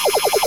Free Combat Sound Effects | FreePixel
parry.mp3